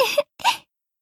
match-start.ogg